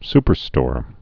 (spər-stôr)